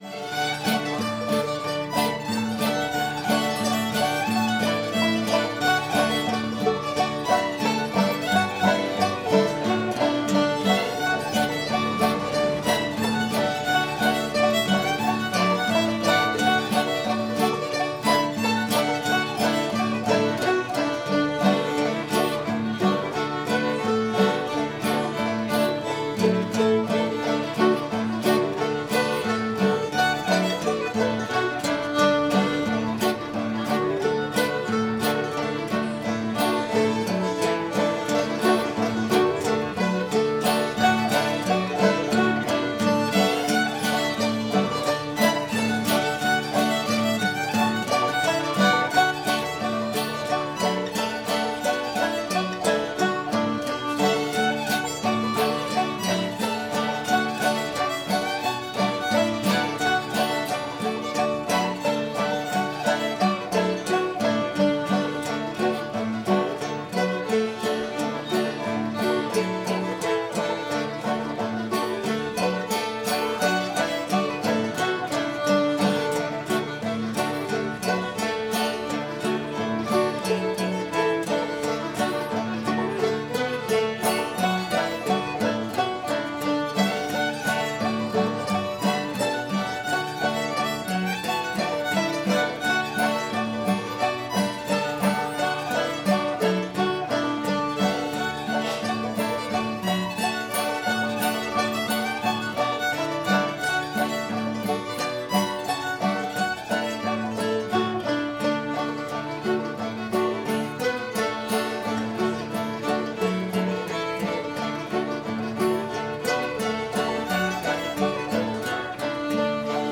lafayette [D]